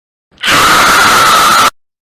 Sound Effects
Extremely Loud Scream